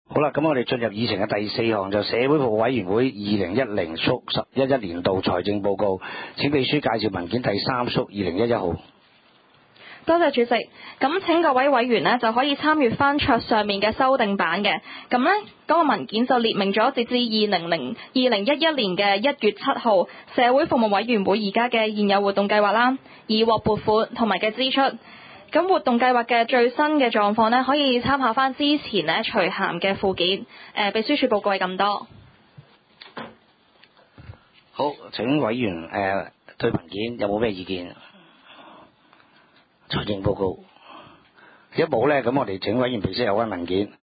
第三屆觀塘區議會屬下 社會服務委員會第二十一次會議記錄 日 期 : 2011 年 1 月 25 日 ( 星期二 ) 時 間 : 下午 2 時 30 分 地 點 : 九龍觀塘同仁街 6 號觀塘政府合署 3 樓觀塘民政事務處會議室 議 程 討論時間 I. 通過上次會議記錄 00:00:41 II.